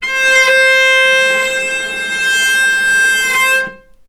vc_sp-C5-ff.AIF